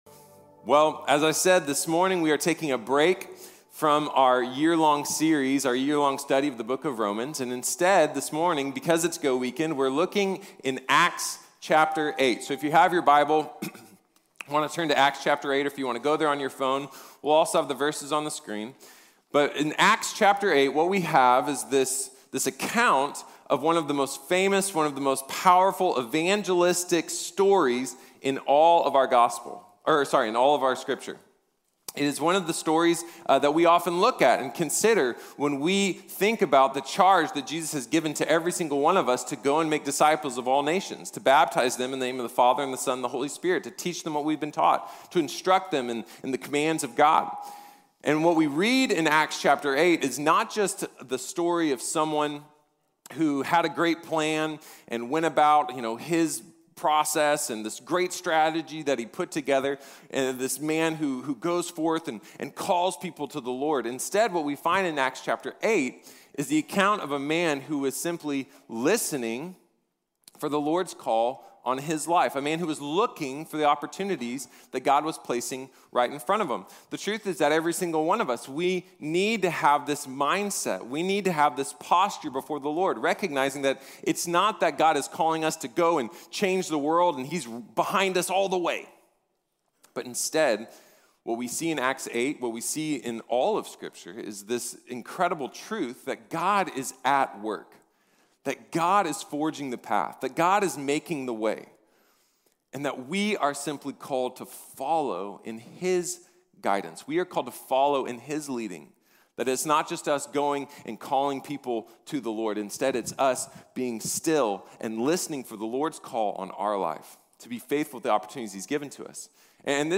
Listen & Look | Sermon | Grace Bible Church